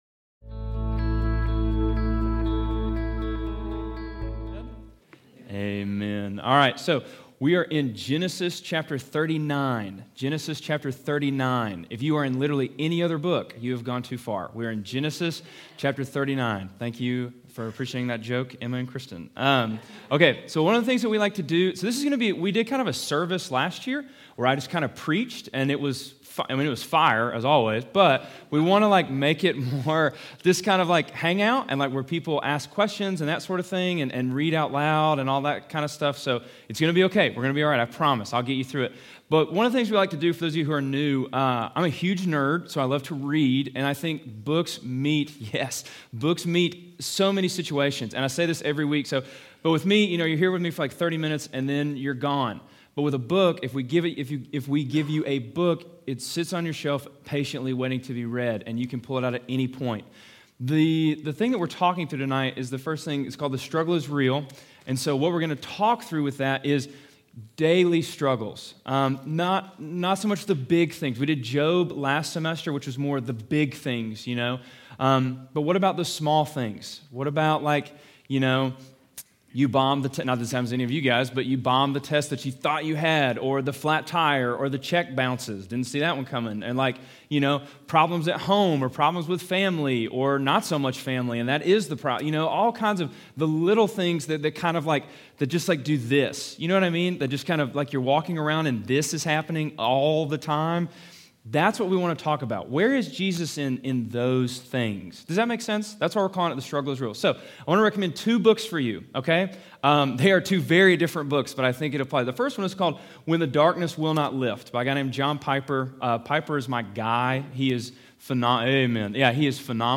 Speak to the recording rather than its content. NOTE: This year we are doing more of a small group feel. You will not be able to hear the verses as students read.